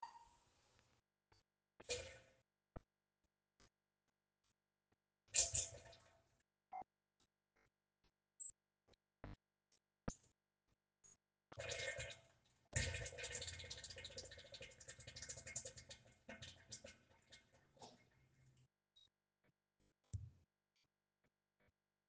Diarrhea Fart - Botão de Efeito Sonoro